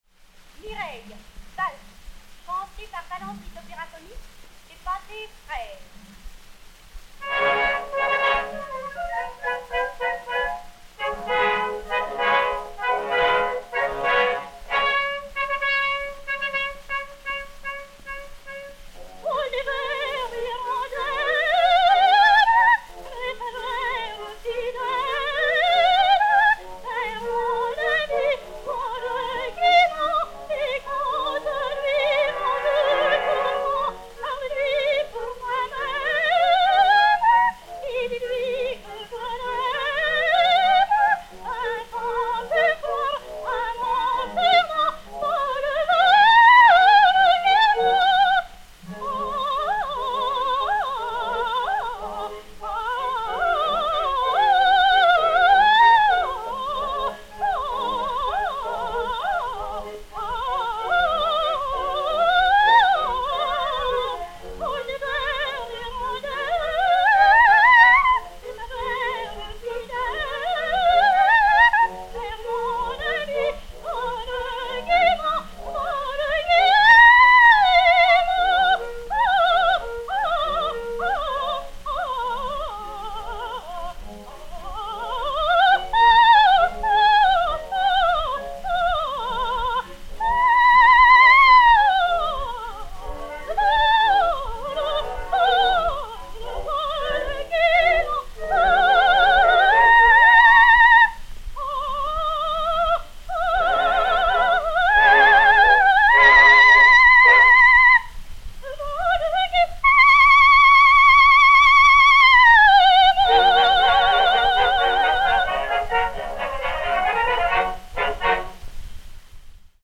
Ariette "O légère hirondelle"
Aline Vallandri (Mireille) et Orchestre
Pathé saphir 90 tours n° 688, réédité sur 80 tours n° 59, enr. en 1910